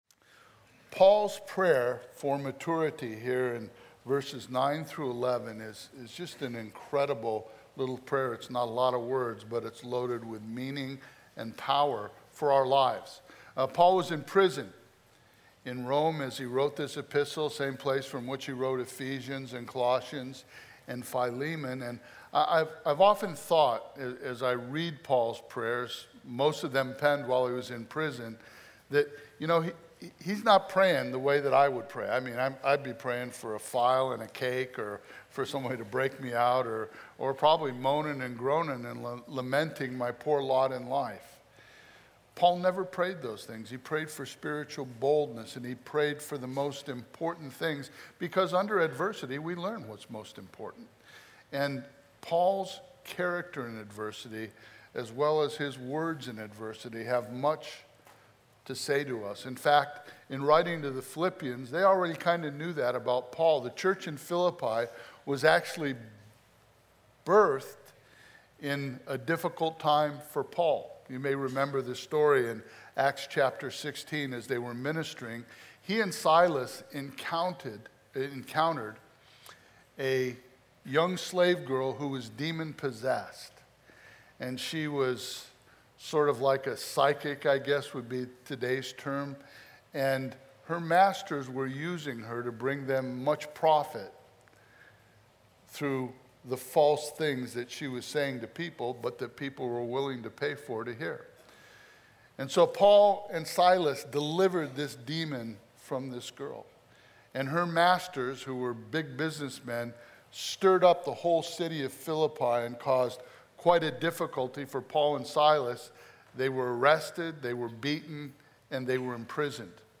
A verse-by-verse expository sermon through Philippians 1:9-11